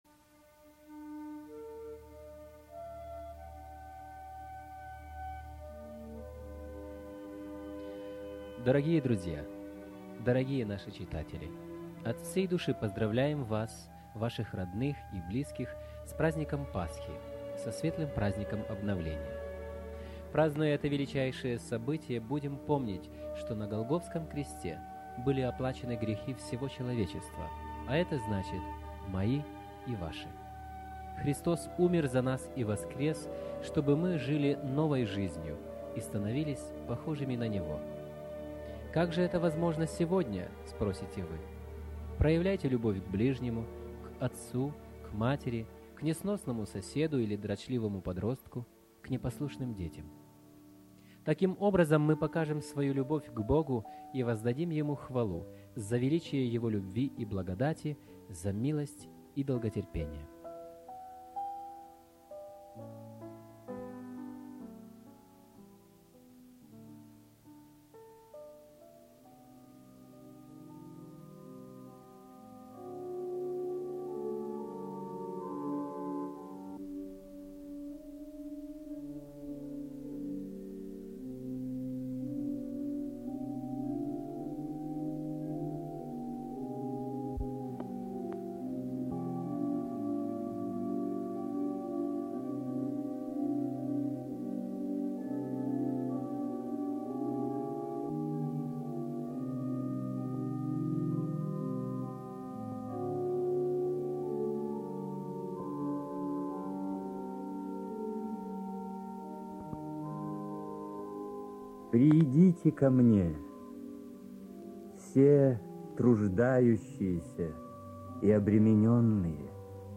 Пасха (Литературно-музыкальная композиция).MP3